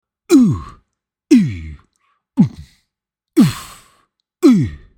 oof.mp3